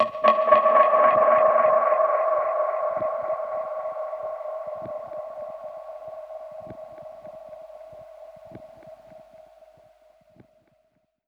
Index of /musicradar/dub-percussion-samples/85bpm
DPFX_PercHit_A_85-07.wav